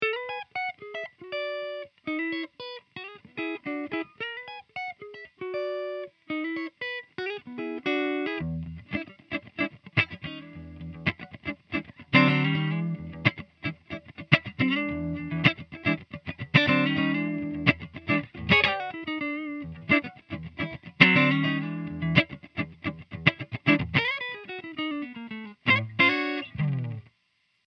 Clean riff 2